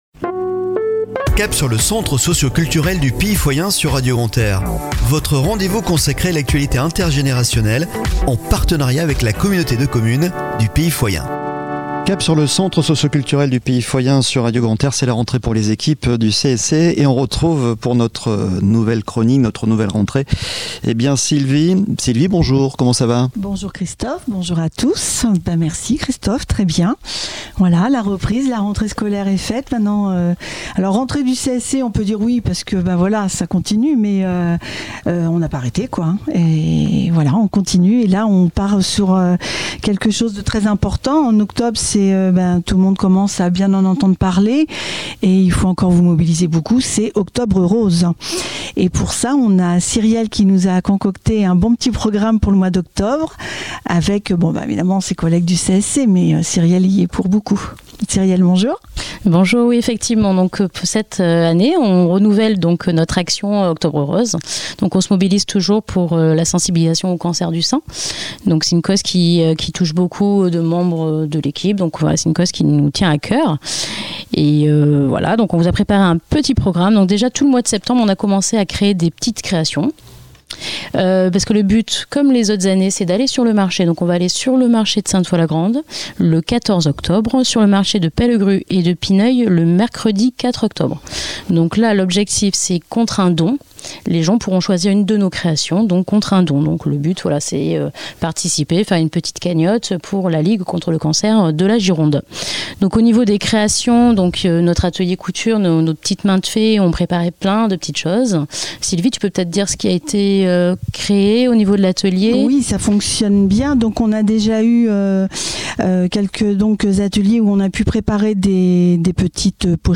Chronique de la semaine du 02 au 08 Octobre 2023 !